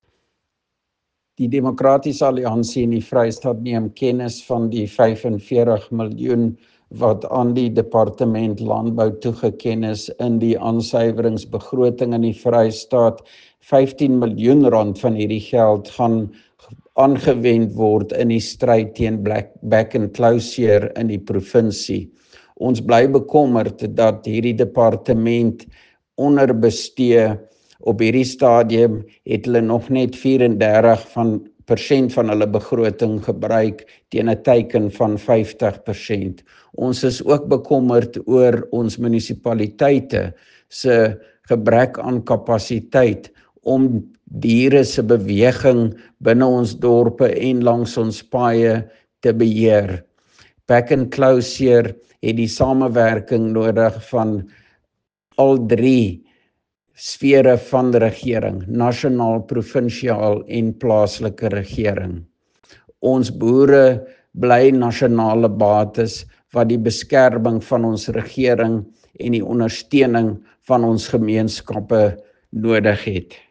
Afrikaans soundbites by Roy Jankielsohn MPL and Sesotho soundbite by Jafta Mokoena MPL